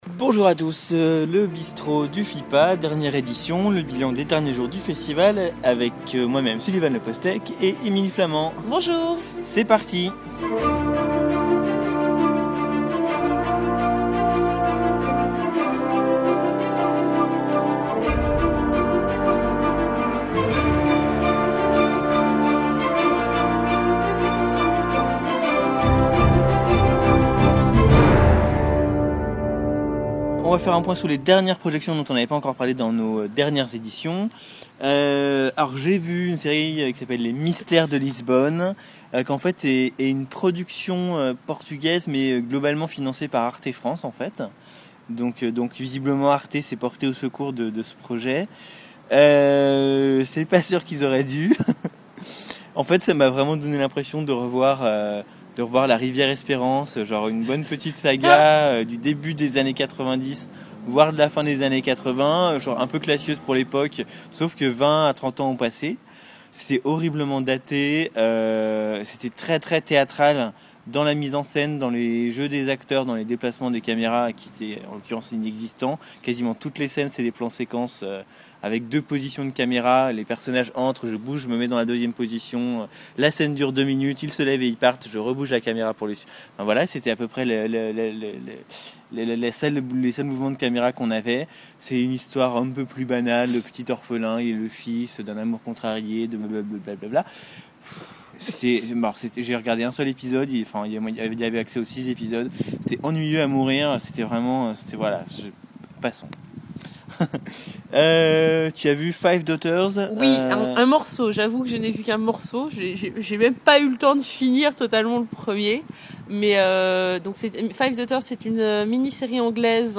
Une discussion informelle qui permet de mettre en lumière nos premiers coups de coeur, quelques coups de griffe, et de vivre au jour le jour l’ambiance de ce FIPA.